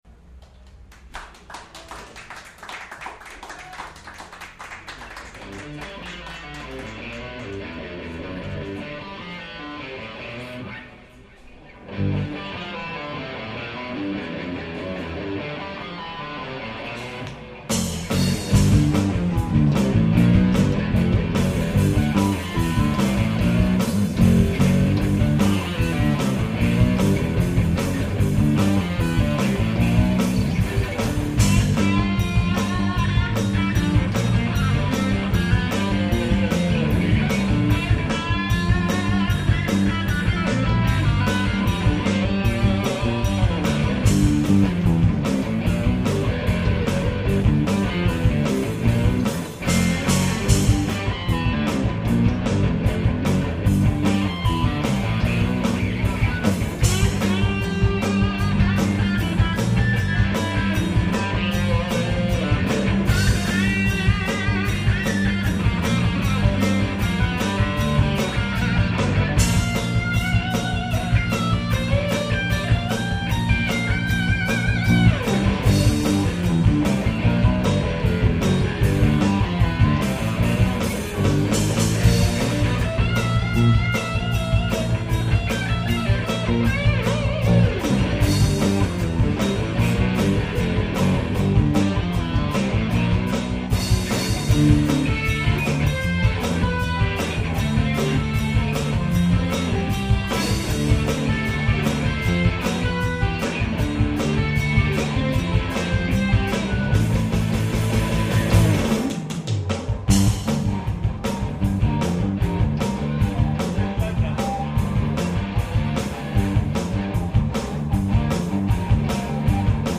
"Live" at the Metaphor Cafe in Escondido, CA
Bass
Electronic Drums